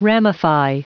Prononciation du mot ramify en anglais (fichier audio)
Prononciation du mot : ramify